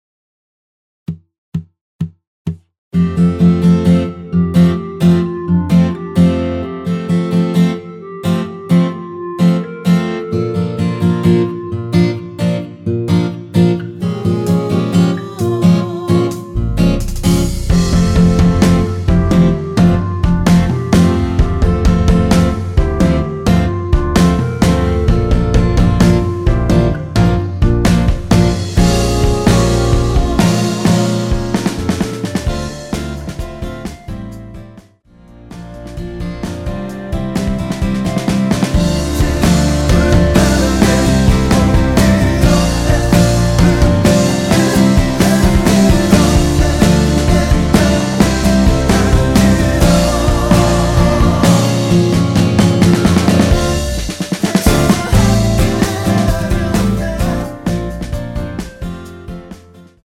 전주 없이 시작하는 곡이라 4박 카운트 넣어 놓았습니다.(미리듣기 확인)
원키 멜로디와 코러스 포함된 MR입니다.
앞부분30초, 뒷부분30초씩 편집해서 올려 드리고 있습니다.